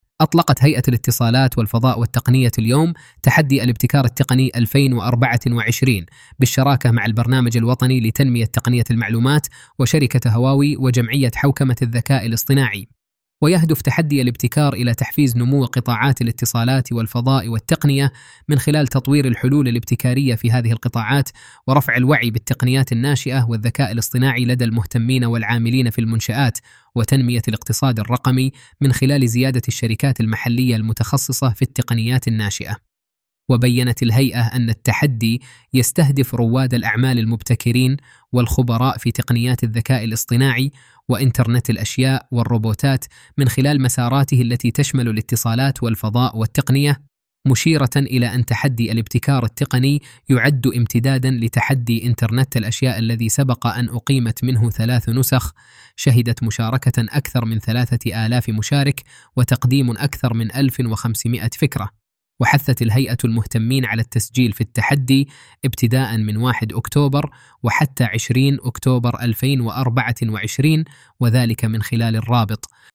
استكشف نماذج عملية اصدرت عن طريق منصتنا من فيديوات، مقاطع صوتية، مقالات، وغيرها ترجمت و دبلجت الى مختلف اللغات